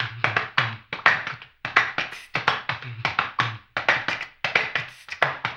HAMBONE 05.wav